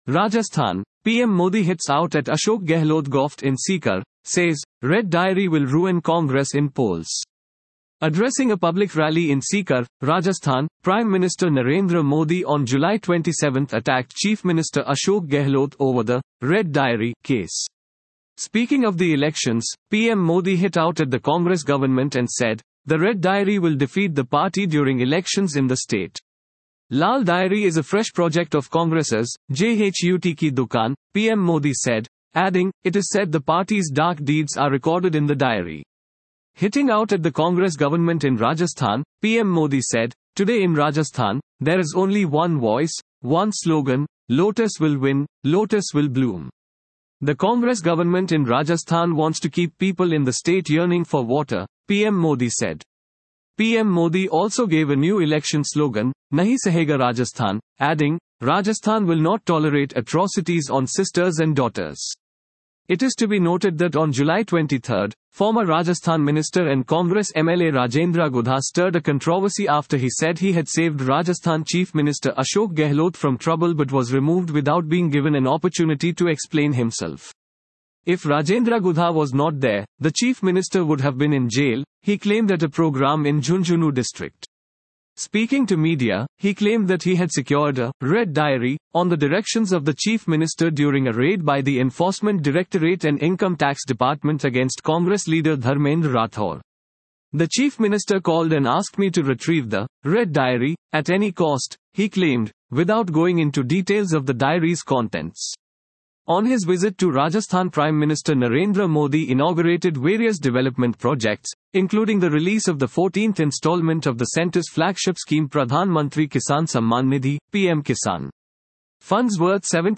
Addressing a public rally in Sikar, Rajasthan, Prime Minister Narendra Modi on July 27 attacked Chief Minister Ashok Gehlot over the 'red diary' case.